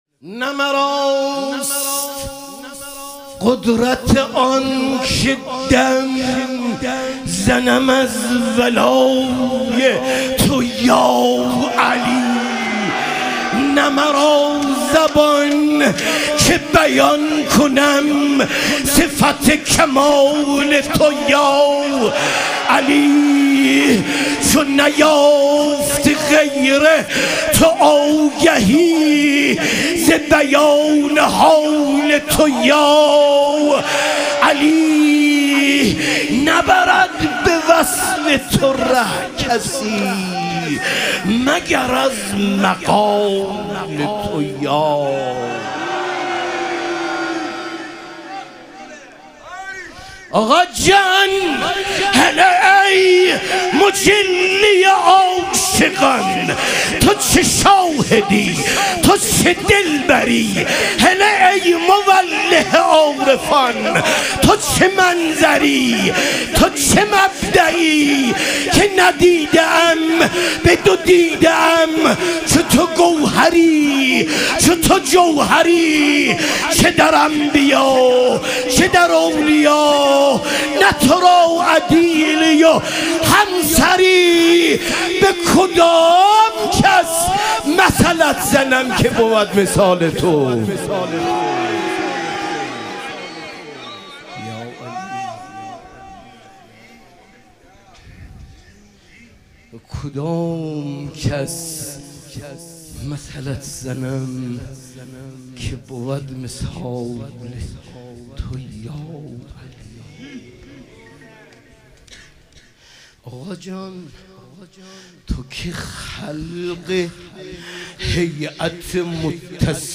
مدح و رجز
شب ظهور وجود مقدس حضرت امیرالمومنین علیه السلام